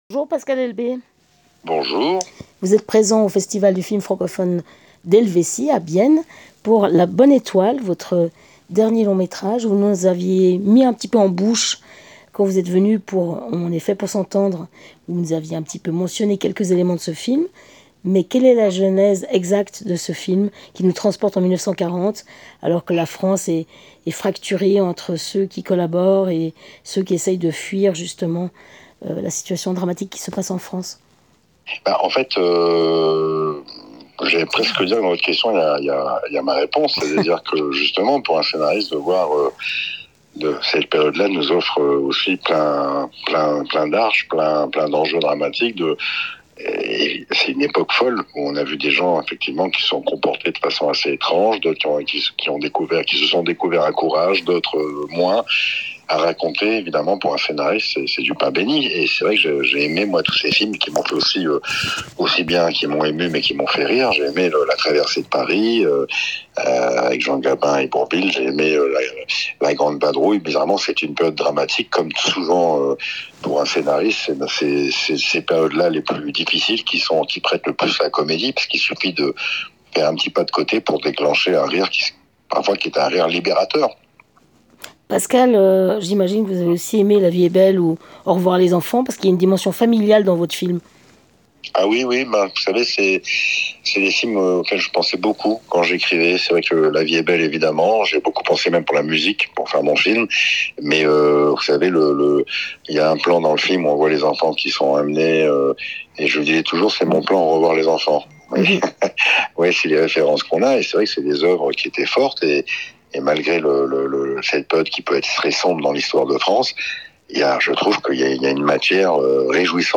La Bonne Étoile, de Pascal Elbé, offre une nouvelle lecture de la période trouble de l'occupation en tordant le cou aux préjugés. Rencontre - j:mag